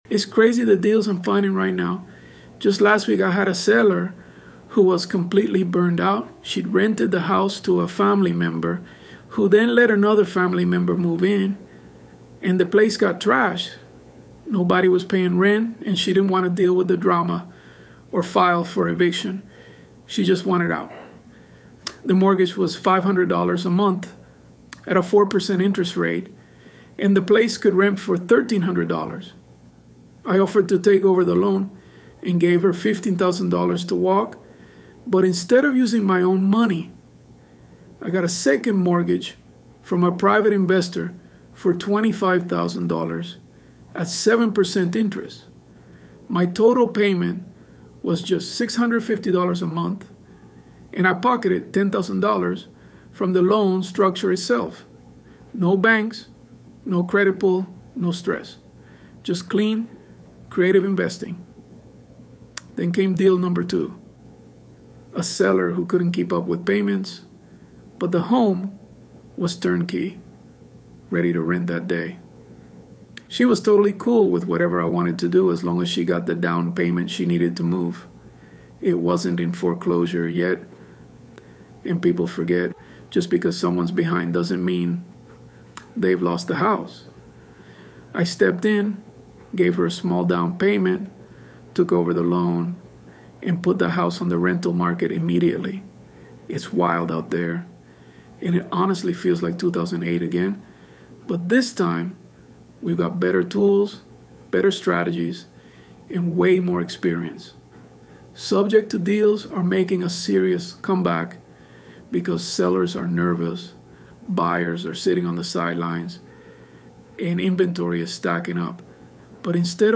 ElevenLabs_Untitled_Project-6.mp3